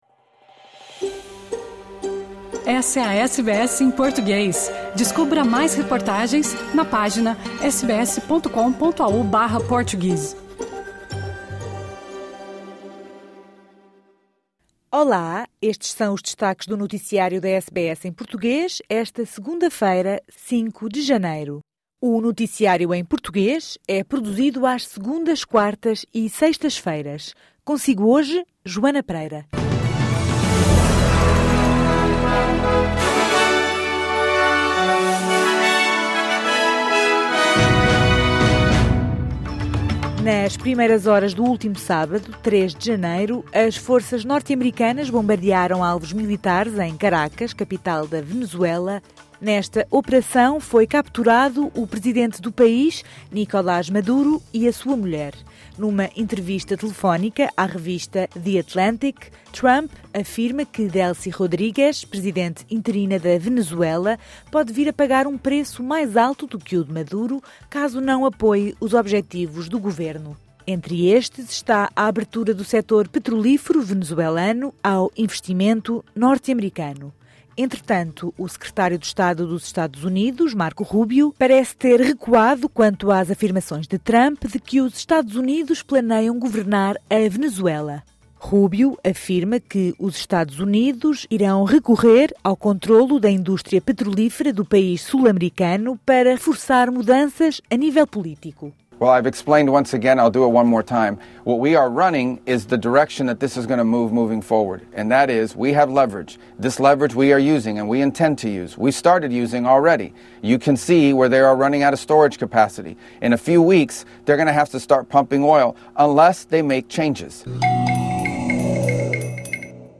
Emitidos avisos de onda de calor intensa para quatro estados da Austrália, com previsões de temperaturas superiores a 40 graus nos próximos dias. Cidadã portuguesa entre as 40 vítimas mortais do incêndio no bar Le Constellation, na estância de ski suíça de Crans-Montana. Estas e outras notícias em destaque no noticiário de hoje.